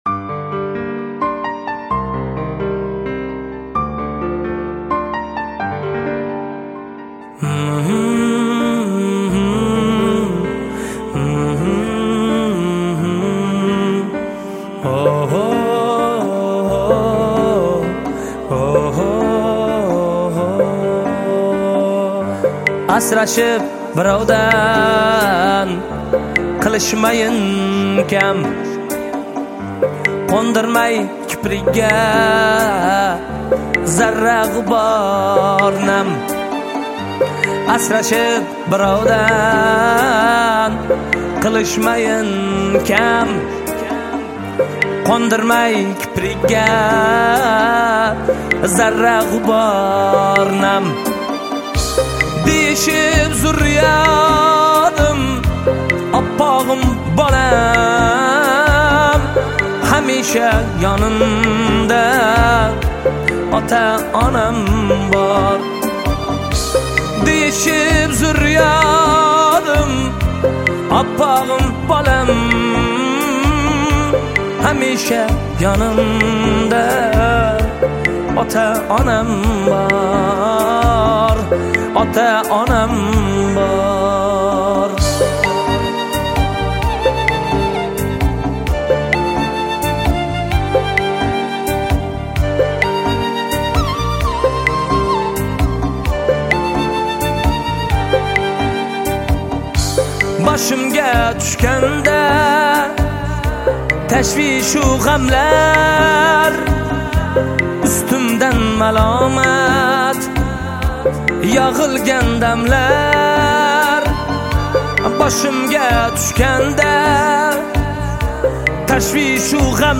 Жанр: Узбекская музыка